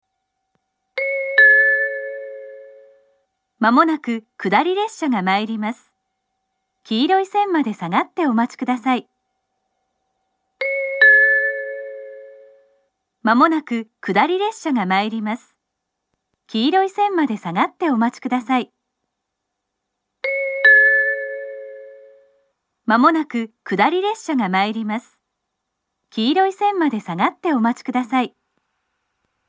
下り接近放送